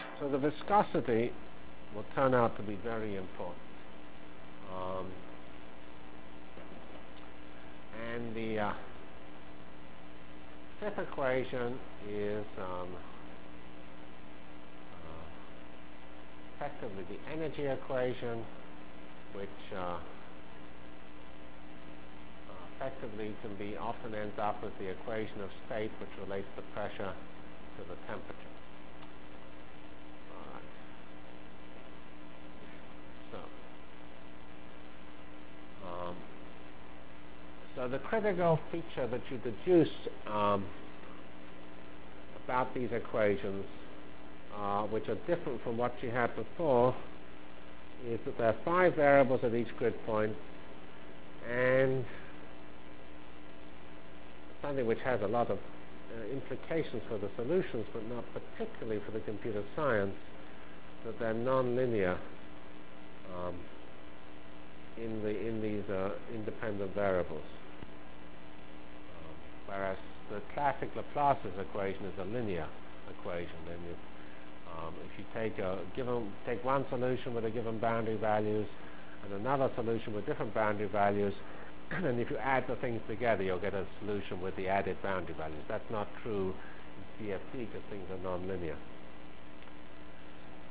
Delivered Lectures of CPS615 Basic Simulation Track for Computational Science -- 14 November 96.